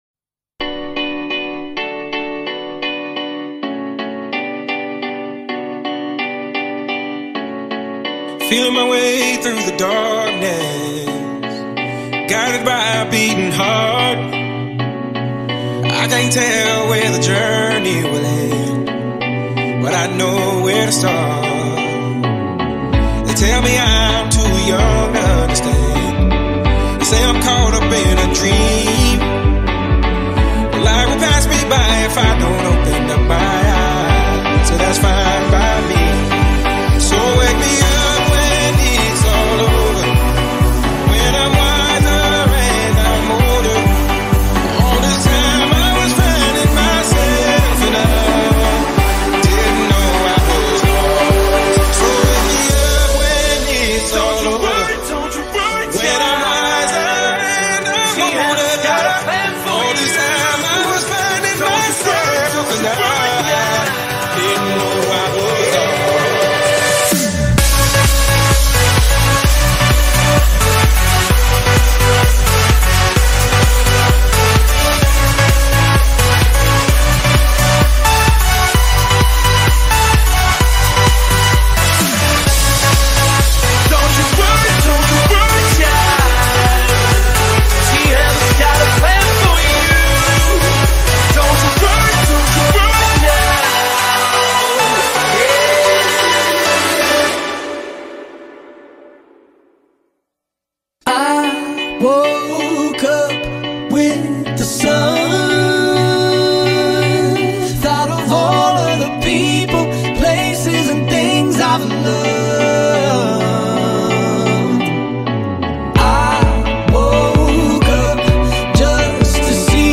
Mashup Remake